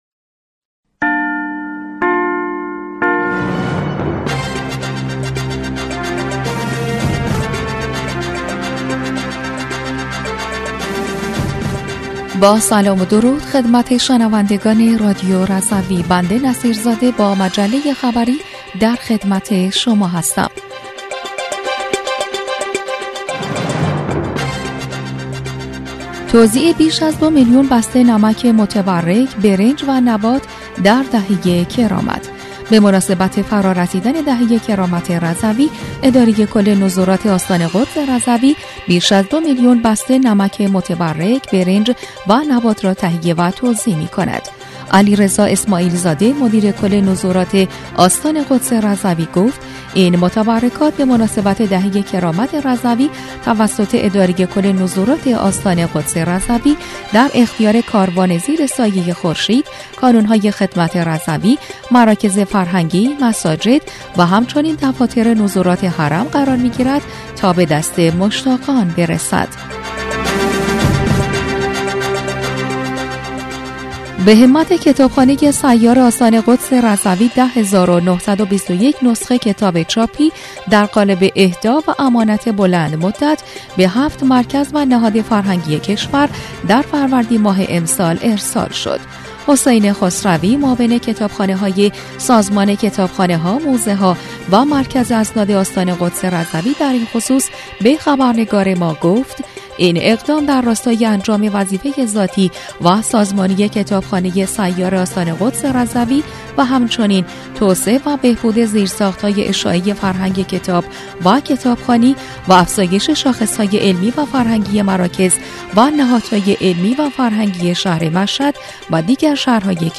بسته خبری دوشنبه ۸ اردیبهشت؛
گوینده خبر